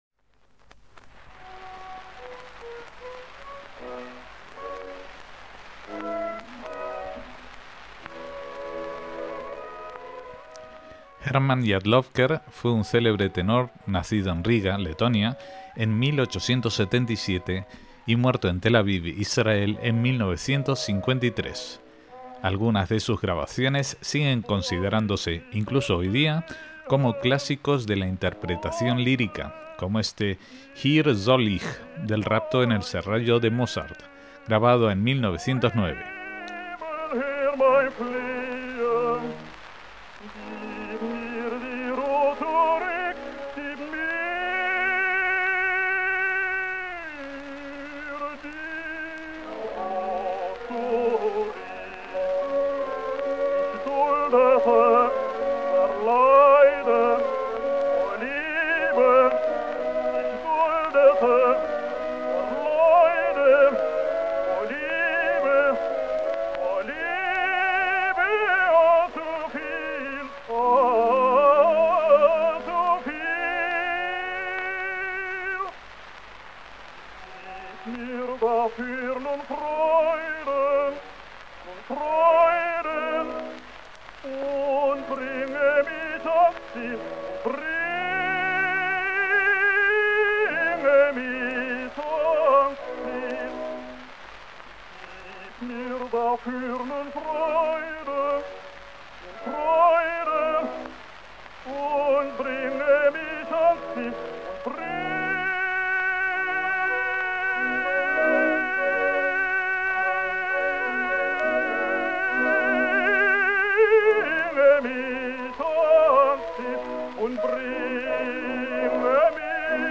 KOLOT: VOCES DE AYER Y DE HOY - Hermann Jadlowker (nacido en Riga en 1877 y muerto en Tel Aviv en 1953) fue un cantante judío de ópera con voz de tenor, y destacado por su coloratura.